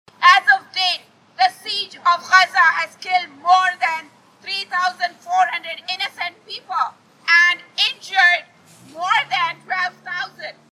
AUDIO: Noisy, peaceful peace demonstration outside Kalamazoo federal courthouse
KALAMAZOO, MI (WKZO AM/FM) – There was a noisy and peaceful demonstration at Kalamazoo’s federal courthouse downtown Thursday evening to call for a peaceful resolution to the violence in the Gaza Strip.
Well over 100 turned out despite a light rain.